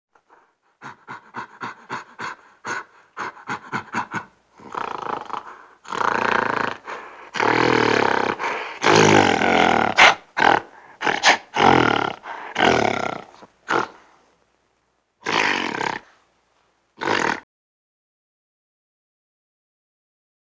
animal
Gorilla Panting with Snorts and Chuffs